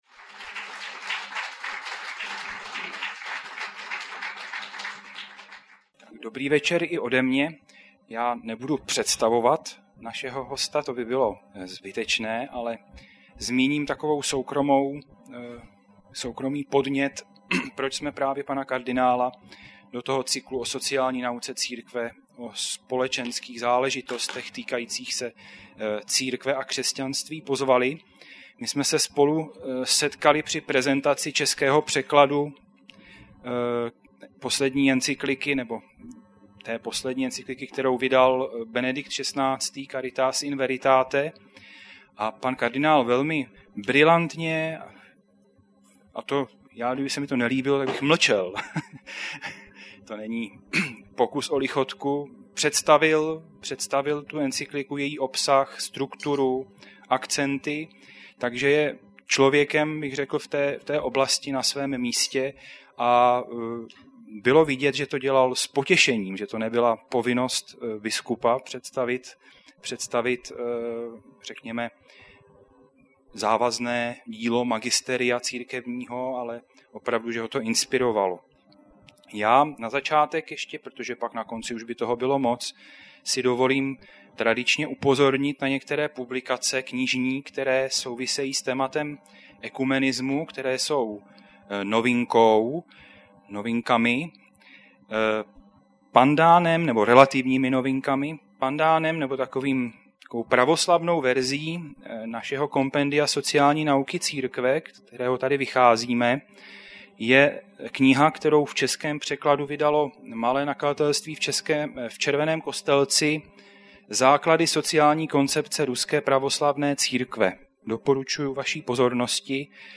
Zvukový záznam přednášky
19. ledna 2011 proběhla další beseda z cyklu Iniciativy 17–11. Hostem setkání byl Miloslav kardinál Vlk.